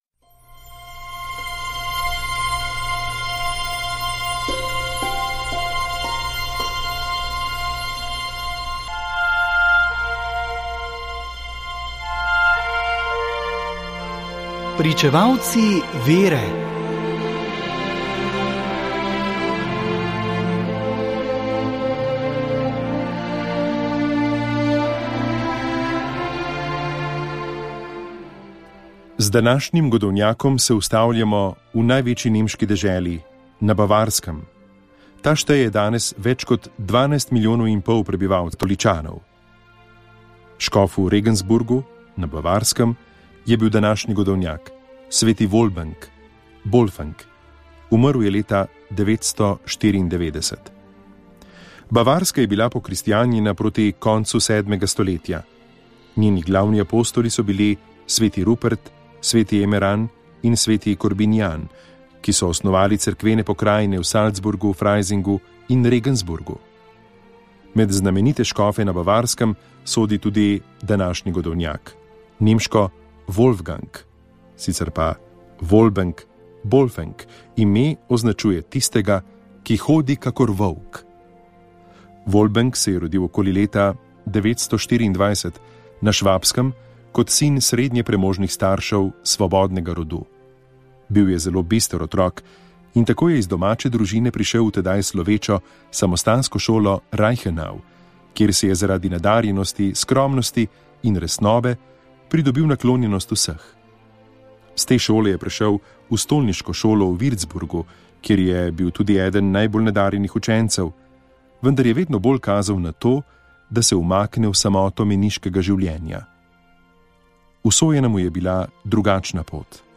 Iz knjige Svetnik za vsak dan Silvestra Čuka se vsak dan na Radiu Ognjišče prebira o svetniku dneva.